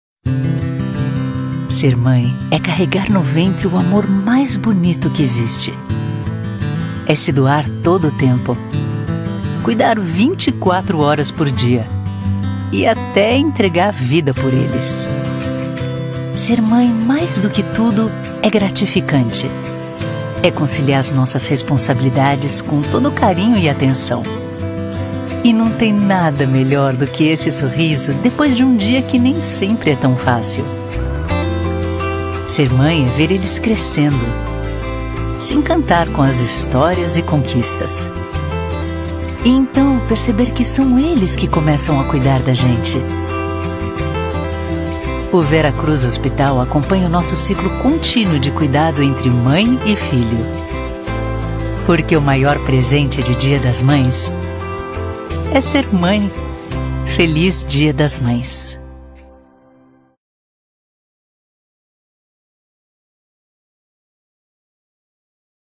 Vidéos d'entreprise
Je suis décrite comme une voix brésilienne chaleureuse et charmante.
Actuellement, mes fichiers audio sont enregistrés dans mon home studio à São Paulo, au Brésil, avec du matériel professionnel.
Mezzo-soprano